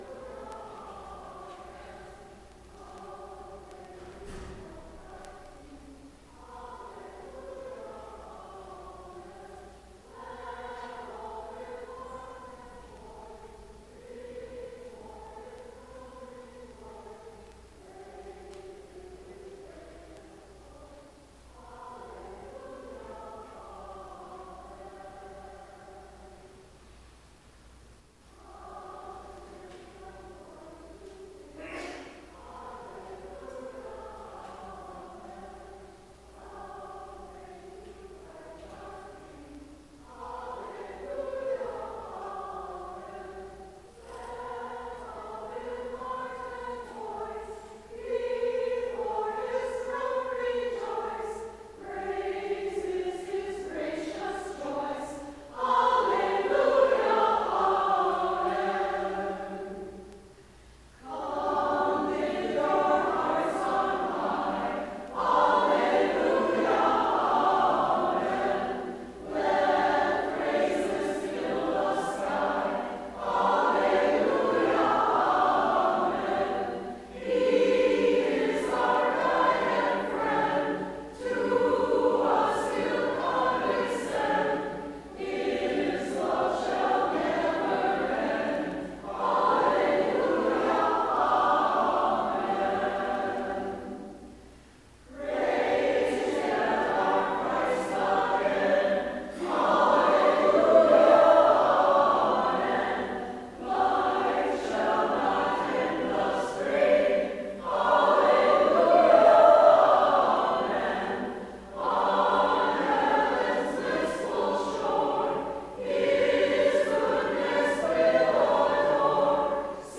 Adult Choir | The First Presbyterian Church Of Dearborn
We help lead worship during the Sunday morning service. Accompanied by our Aeolian Skinner Pipe Organ, the piano, or various other instruments, we sing everything from music of the masters to music written yesterday.